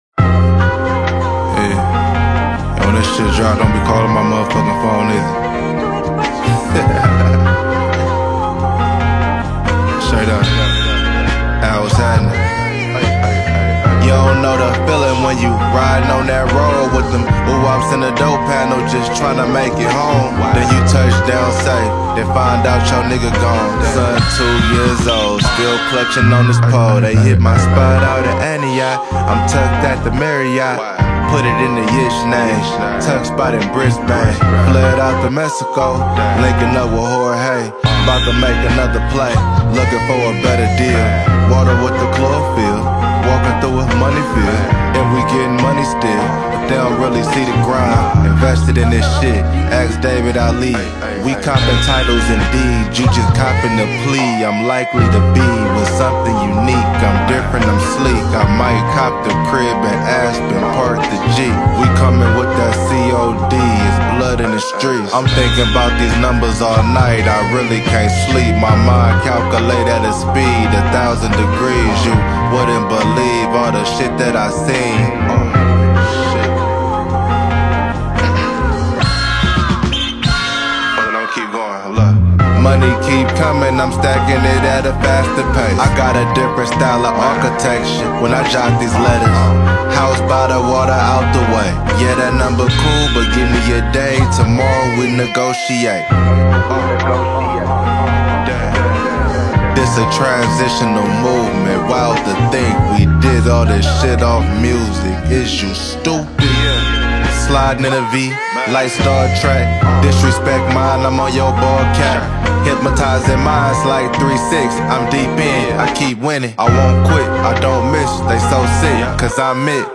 Hip Hop
American rapper